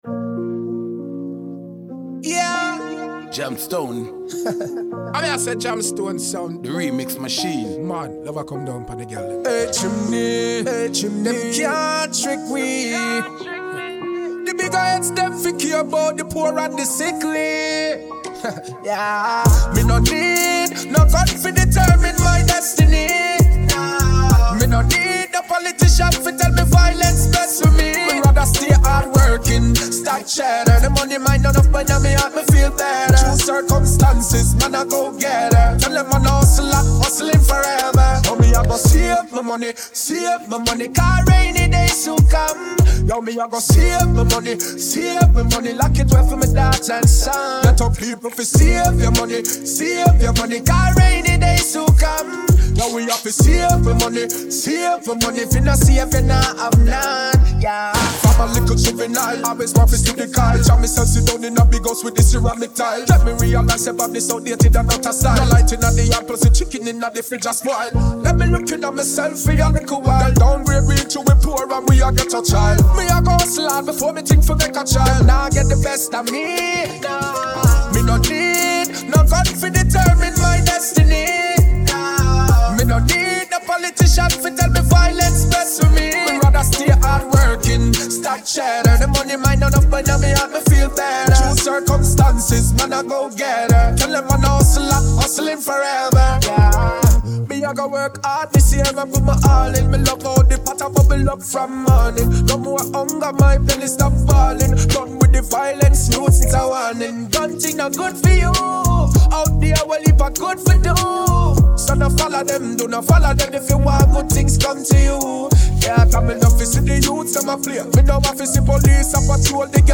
Vocal track